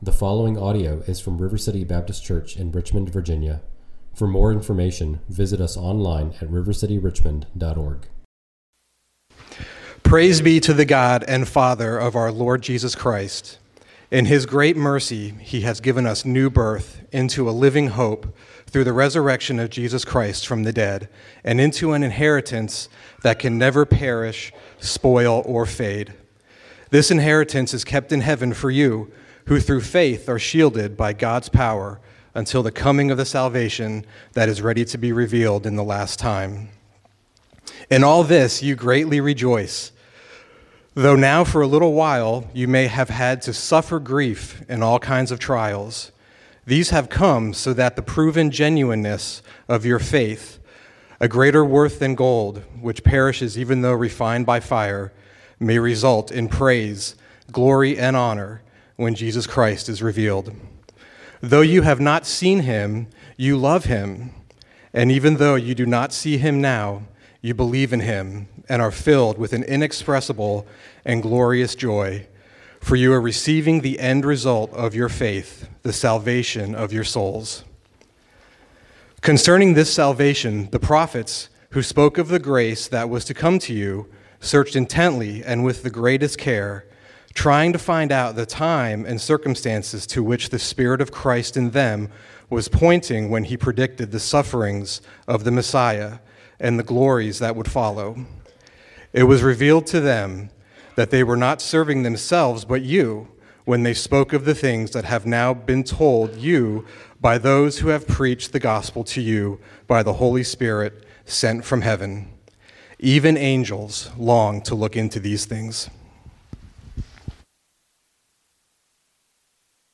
preaches 1 Peter 1:3–12 at River City Baptist Church, a new congregation in Richmond, Virginia.